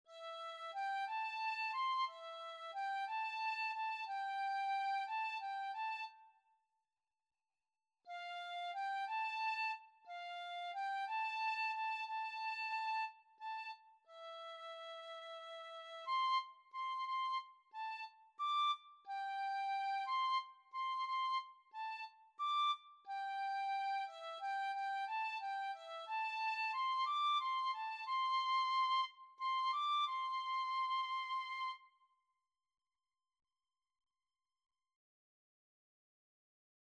Free Sheet music for Tin Whistle (Penny Whistle)
3/4 (View more 3/4 Music)
Pop (View more Pop Tin Whistle Music)